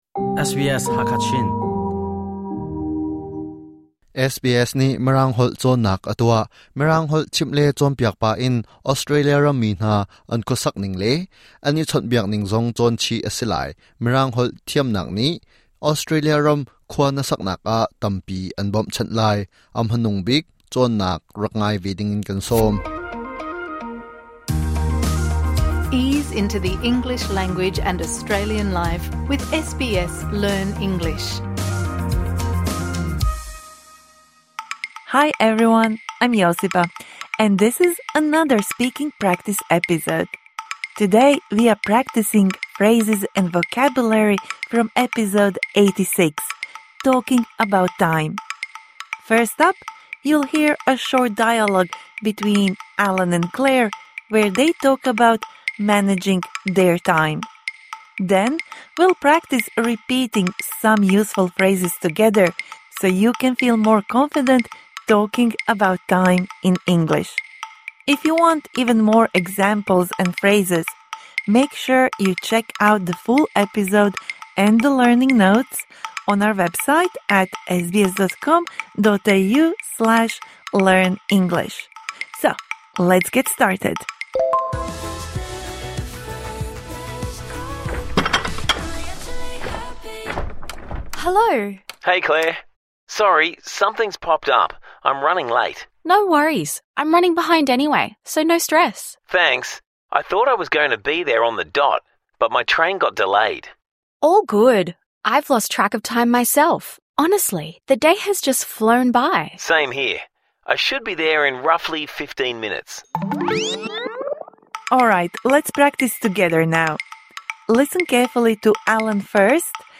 Learn the meanings of the phrases used in this dialogue: #86 When?